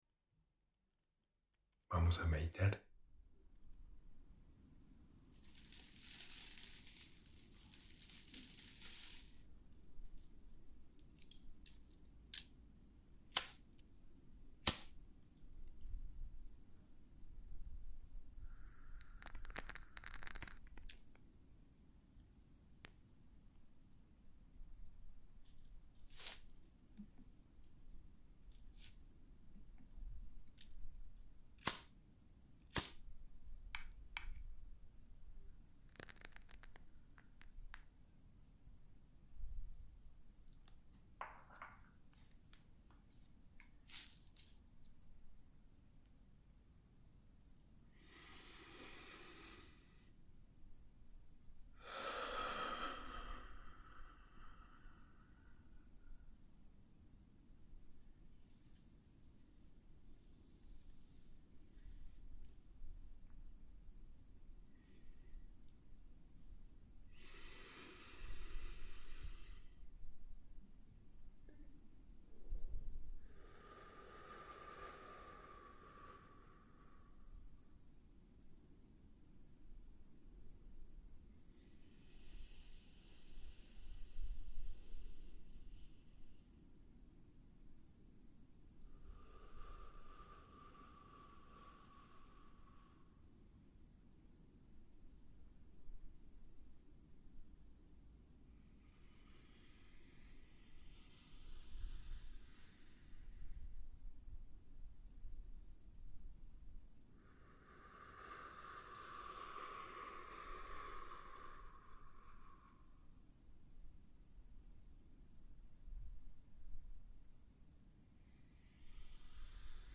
Meditación en tiempo real para conectar en la misma frecuencia Hosted on Acast.